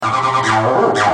Sons et loops gratuits de TB303 Roland Bassline
Basse tb303 - 41